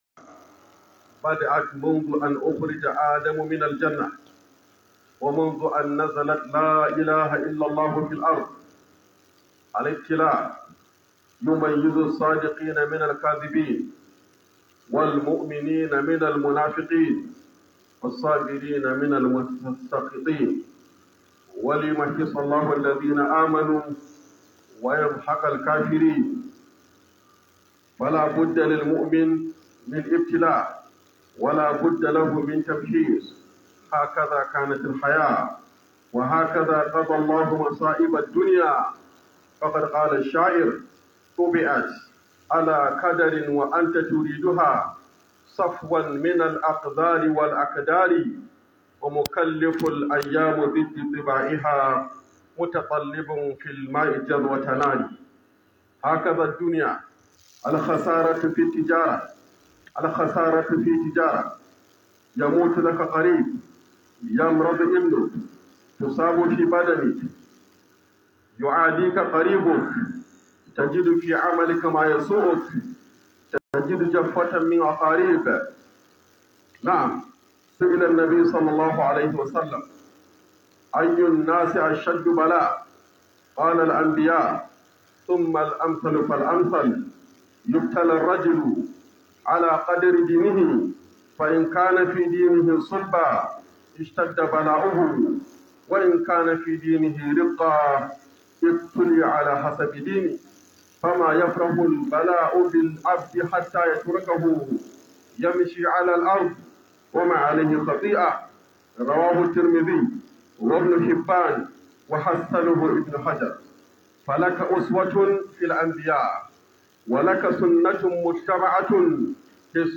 1446-2024 KHUDUBAN JUMMA'A